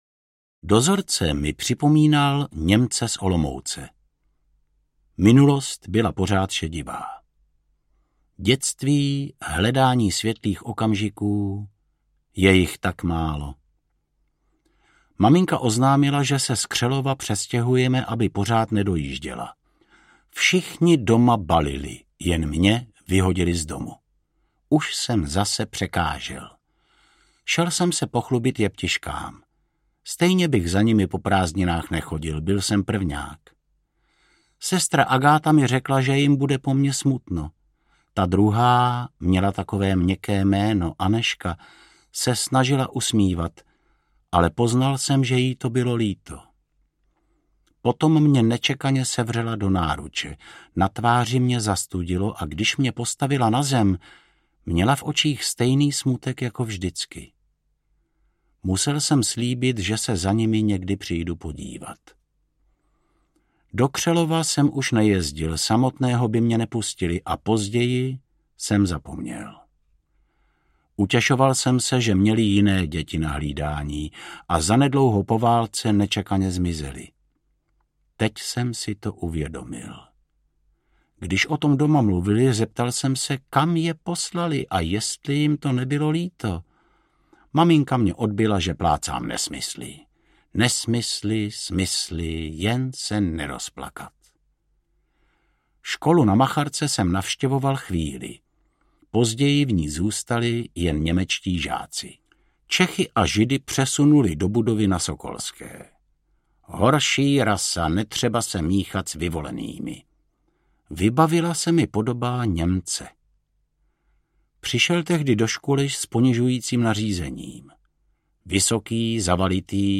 Ukázka z knihy
Vyrobilo studio Soundguru.